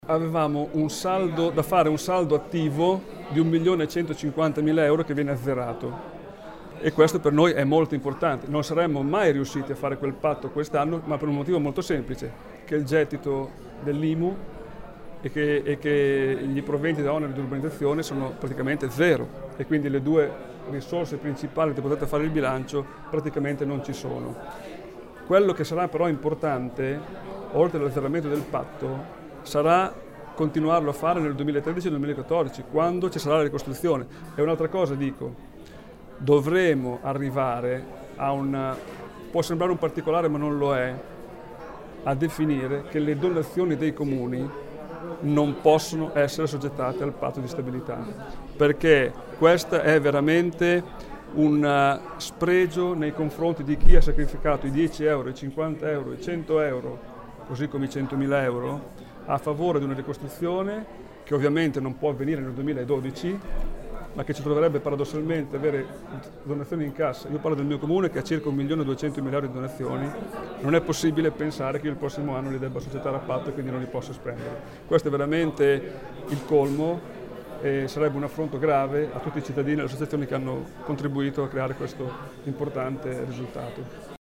Il sindaco di Crevalcore Claudio Broglia ci spiega come le difficoltà saranno grandi anche nel 2013